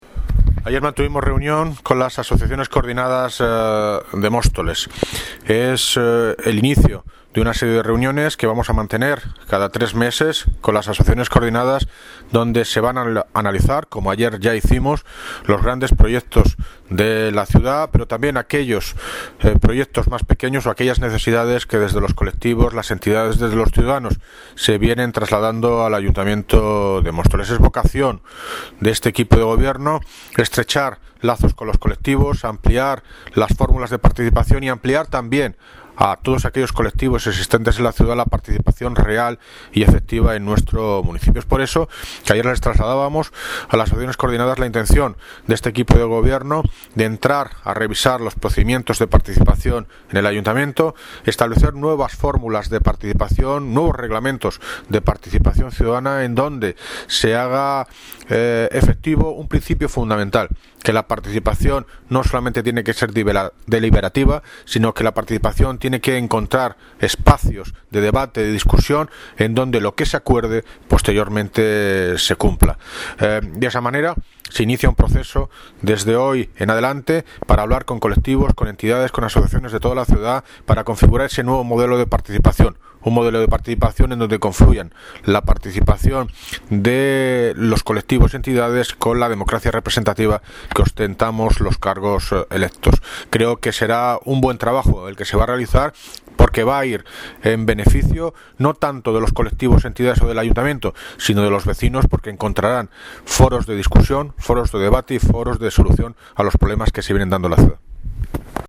Audio - David Lucas (Alcalde de Móstoles) Sobre reunión Coordinadas